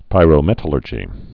(pīrō-mĕtl-ûrjē)